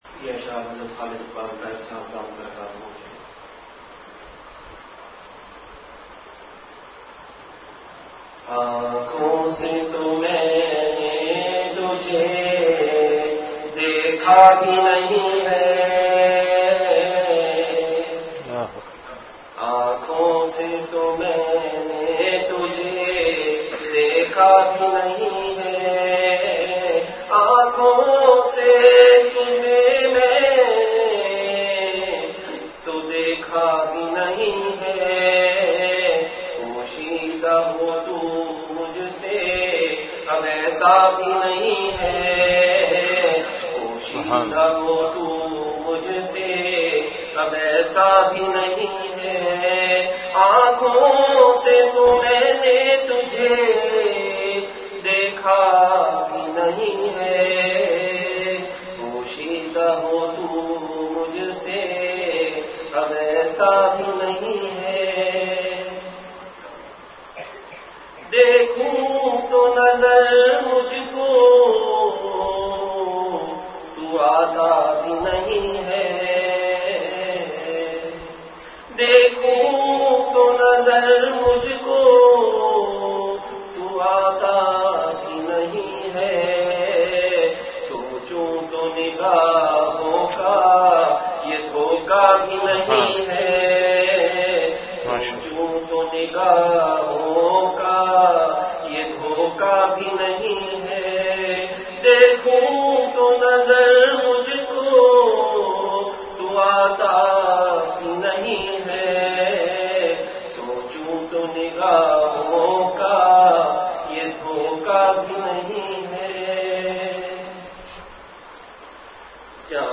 Delivered at Home.